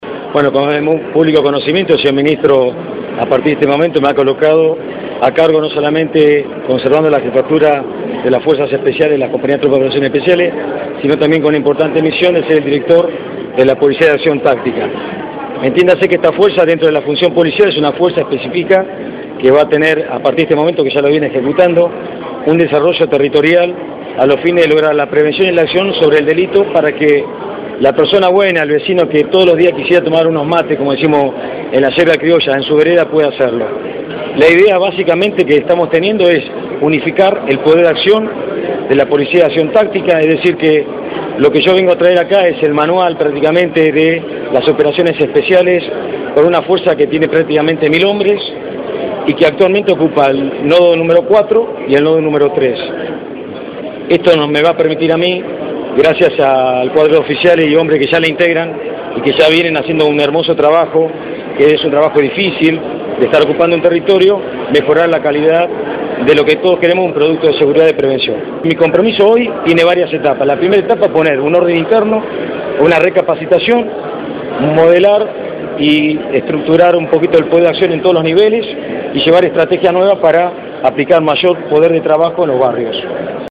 Declaraciones del comisario Adrián Forni.